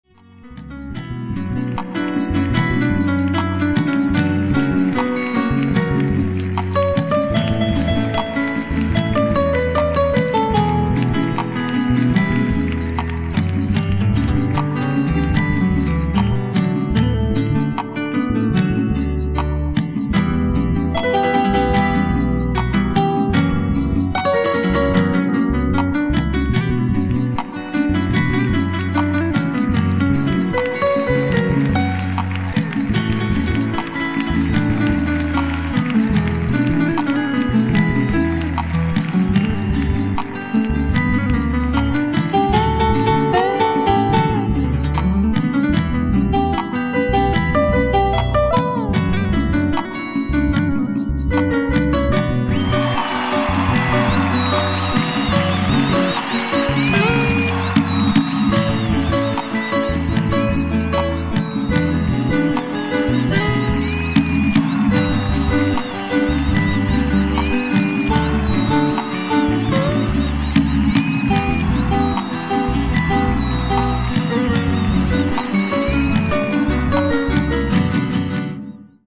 GIGA音色